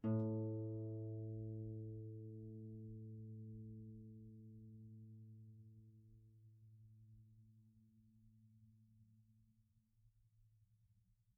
KSHarp_A2_mf.wav